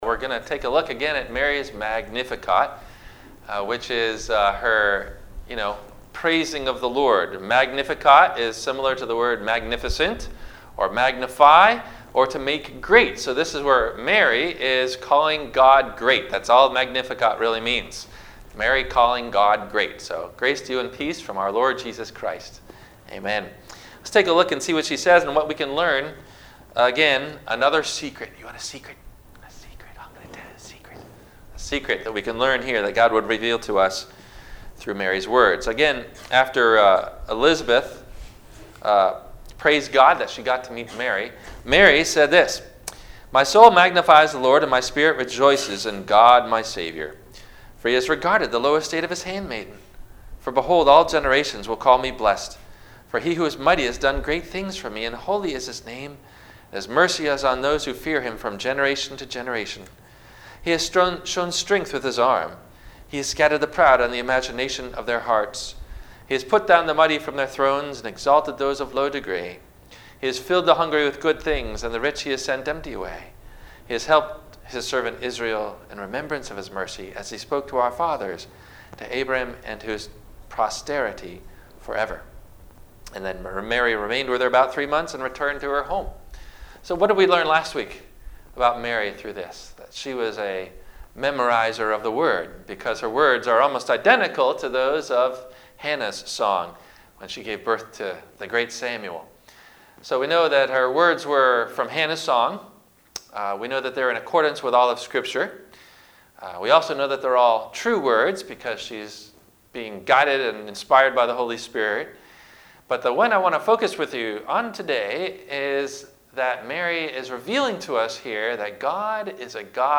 Mary's Magnificat - Secret #2 - Advent Week 2 - Wednesday - Sermon - December 11 2019 - Christ Lutheran Cape Canaveral